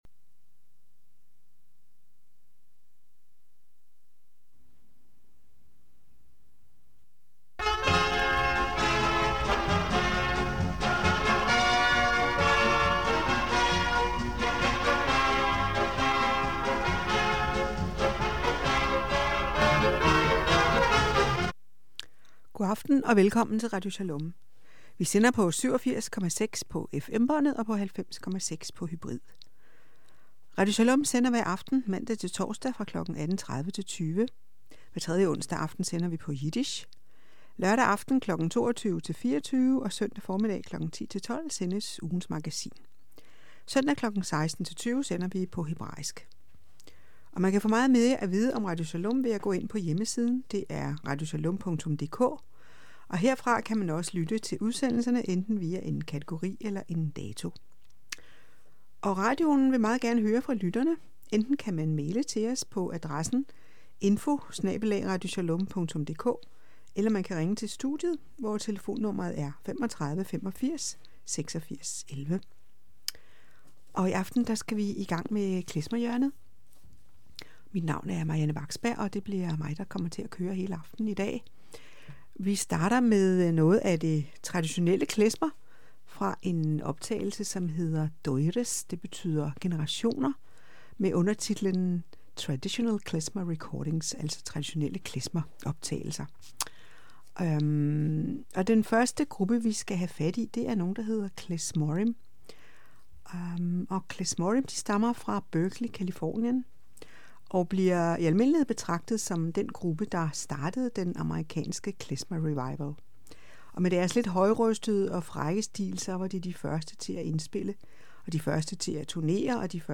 Klezmer